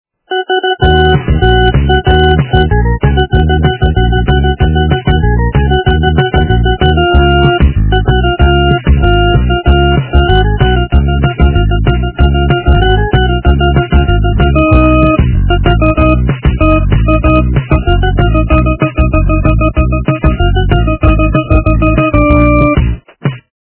западная эстрада
качество понижено и присутствуют гудки
полифоническую мелодию